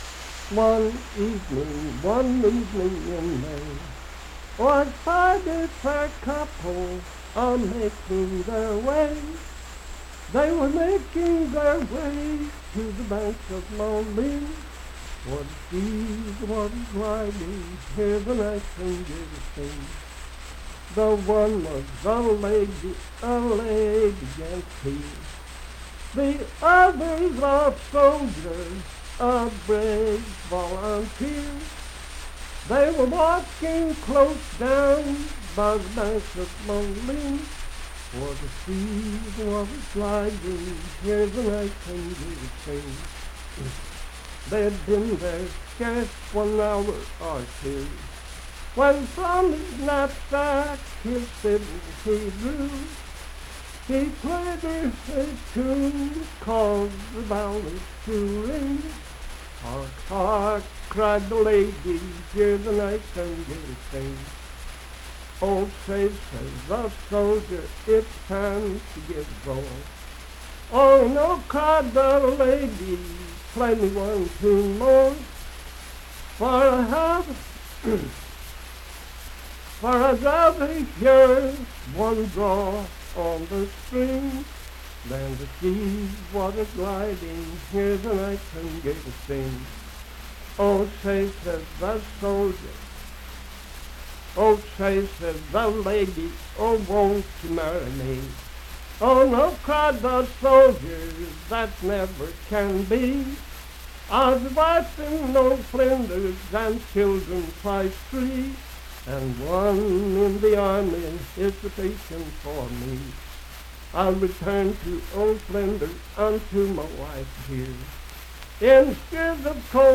Unaccompanied vocal music
in Mount Storm, W.V.
Verse-refrain 6(4).
Voice (sung)